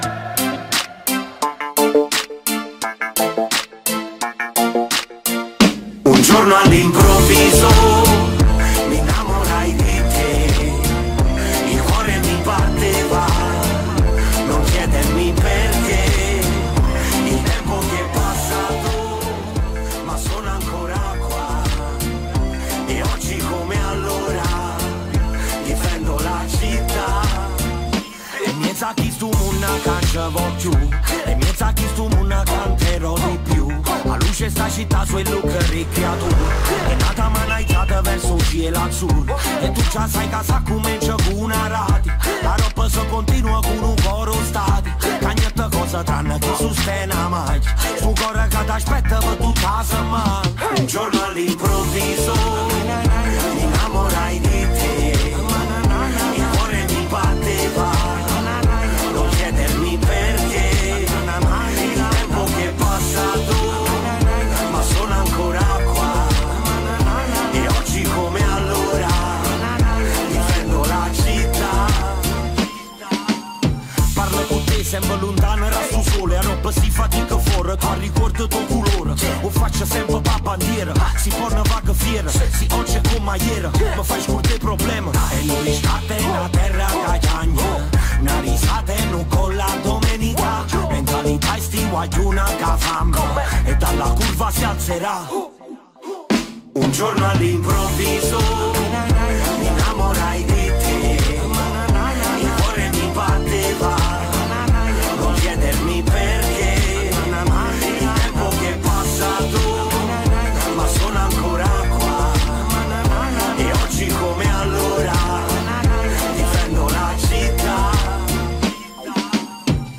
No te pierdas el contenido de este podcast interesante dedicado a la música y al deporte italiano.